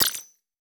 HiTech Click 6.wav